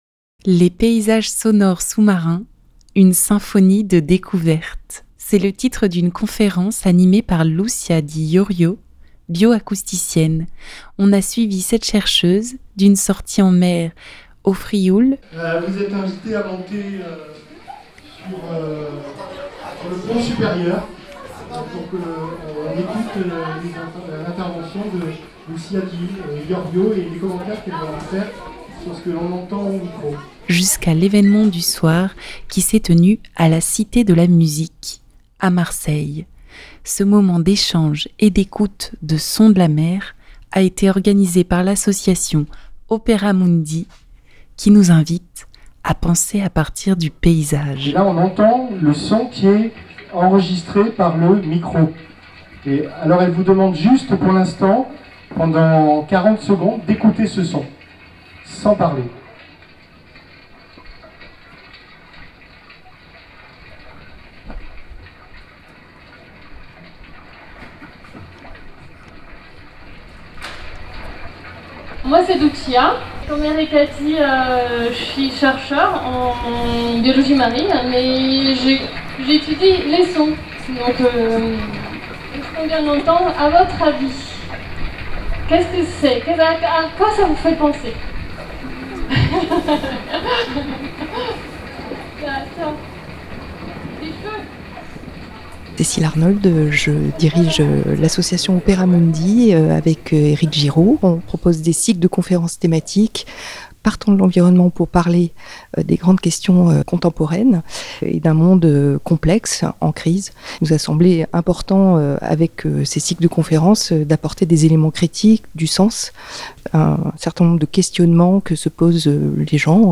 Ce moment d'échange et d'écoute des sons de la mer a été organisé par l'association Opera Mundi qui nous invite à penser à partir du paysage.